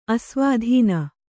♪ asvādhīna